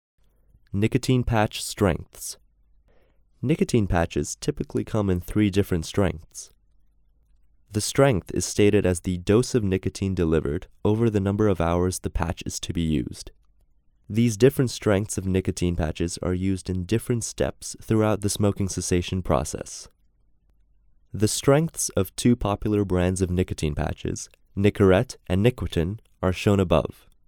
Narration audio (WAV)